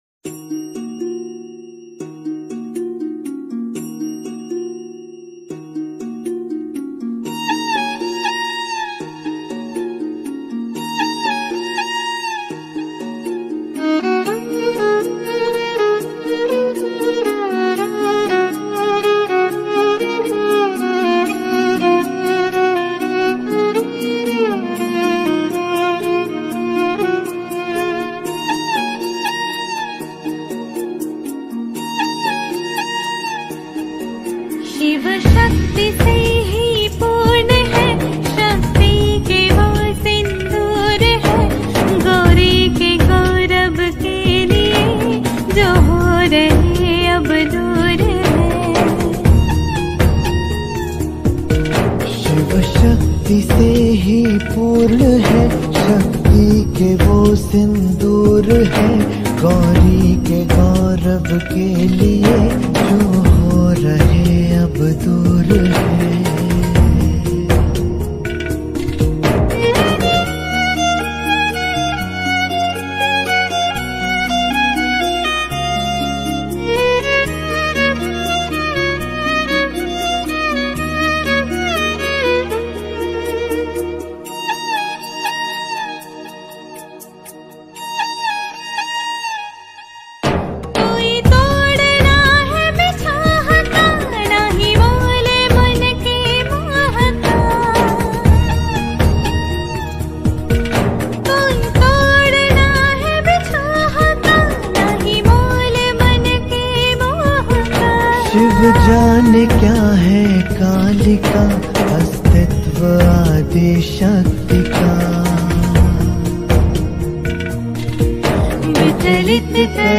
Releted Files Of Bhakti Gana